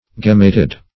Gemmated \Gem"ma*ted\